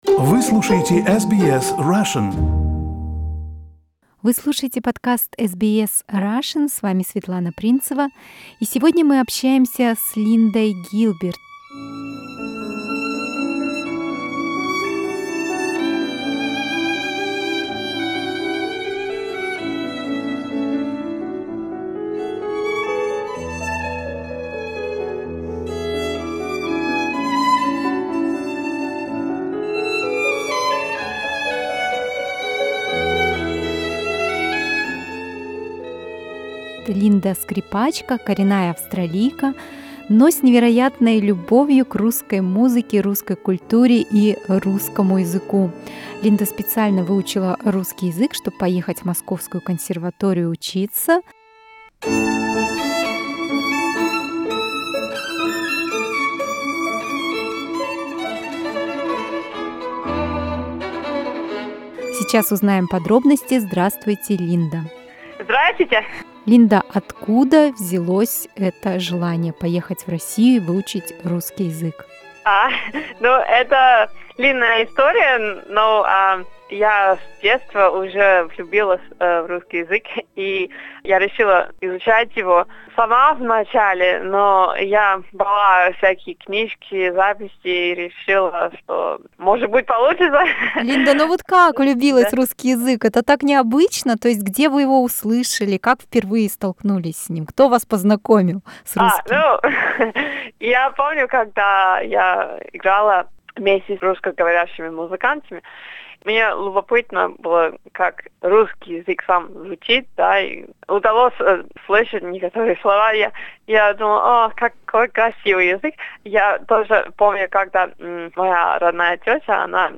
В интервью на русском языке она рассказывает о снеге, Московской консерватории и своей любви к русской культуре.